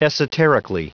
Prononciation du mot : esoterically